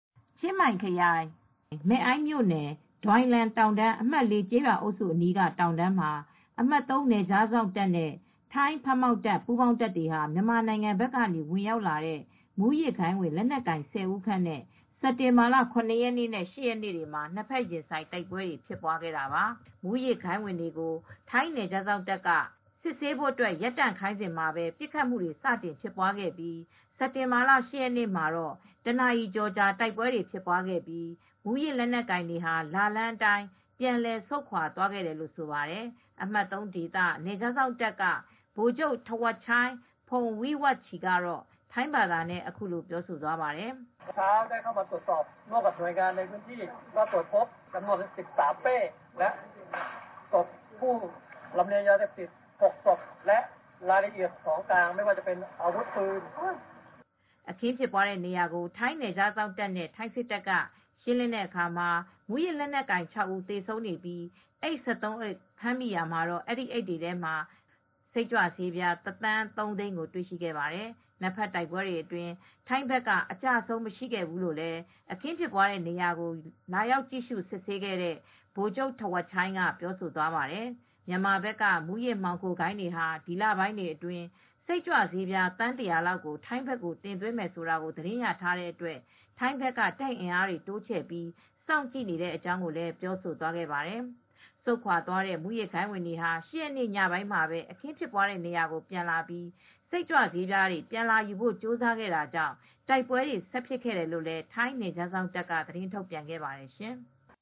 အမှတ် (၃) ဒေသ နယ်ခြားစောင့်တပ်က Pol. Maj. Gen. Thawatchai Phongwiwatchai (ဗိုလ်ချုပ် ထဝပ်ချိုင်း ဖုန်ဝိဝပ်ချီ) က ထိုင်းဘာသာနဲ့ အခုလိုပြောသွားပါတယ်။